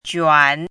怎么读
juǎn quán
juan3.mp3